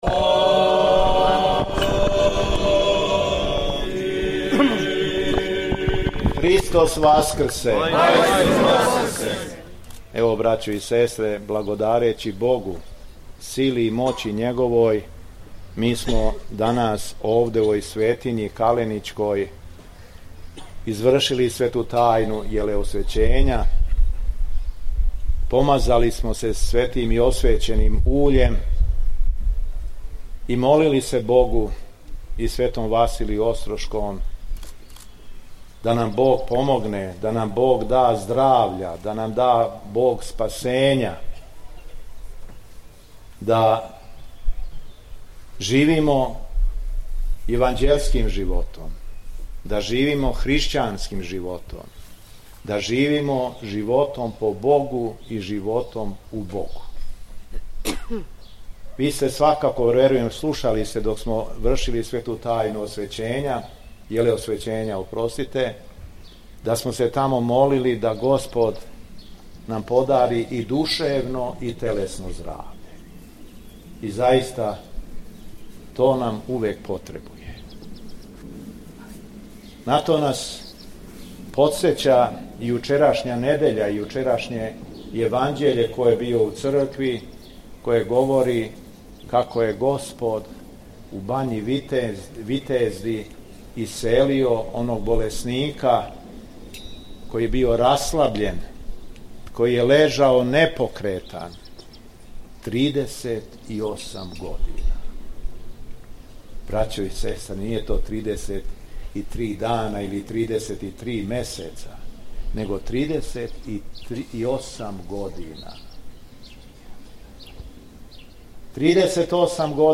У понедељак, 12. маја 2025. године, када наша Света Црква прославља и слави Светог Василија Острошког Чудотворца, Његово Високопреосвештенство Митрополит шумадијски Господин Јован служио је Свету Тајну Јелеосвећења и Свету Архијерејску Литургију у манастиру Каленић.
Беседа Његовог Високопреосвештенства Митрополита шумадијског г. Јована